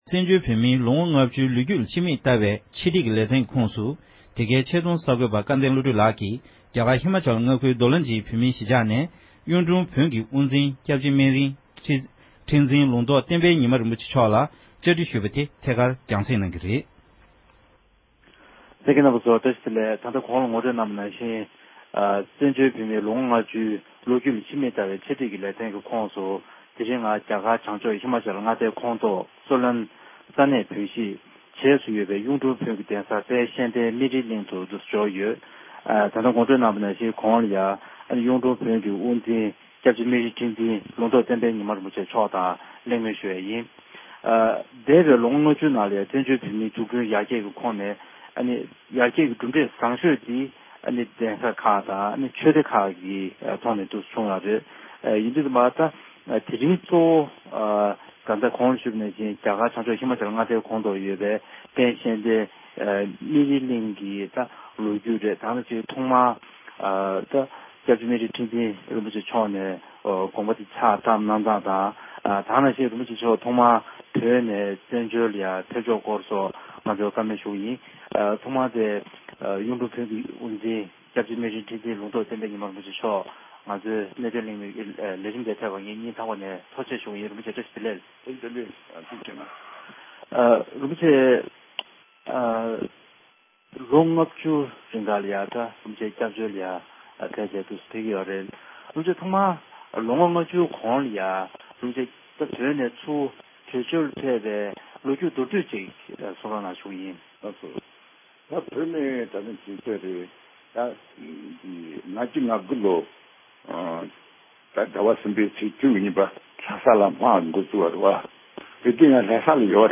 གཡུང་དྲུང་བོན་གྱི་དབུ་འཛིན་༸སྐྱབས་རྗེ་སྨན་རི་ཁྲི་འཛིན་ལུང་རྟོག་བསྟན་པའི་ཉི་མ་རིན་པོ་ཆེ་མཆོག་གི་ལྷན་གླེང་མུལ་ཞུས་པ།